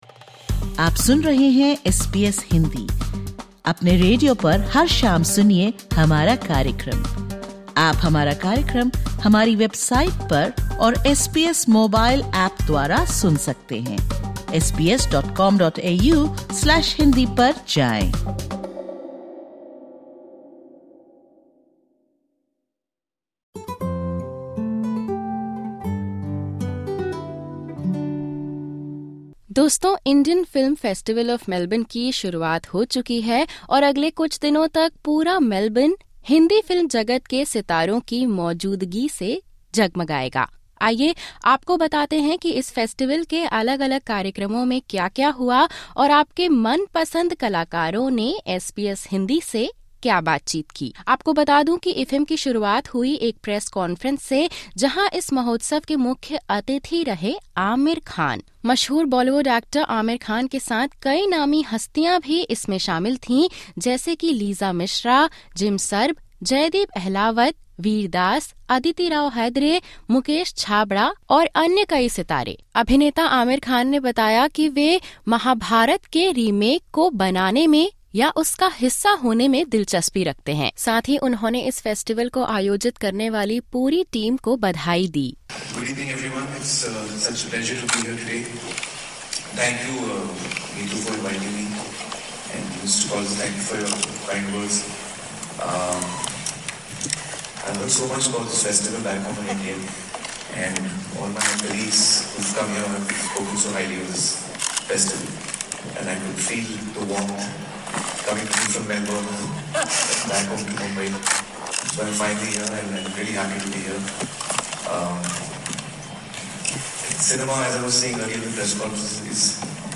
The 16th edition of the Indian Film Festival of Melbourne (IFFM) is celebrating Indian cinema in Australia. This podcast covers key highlights from the award night to the flag hoisting and features your favourite stars sharing how the festival connects with audiences beyond cultural boundaries.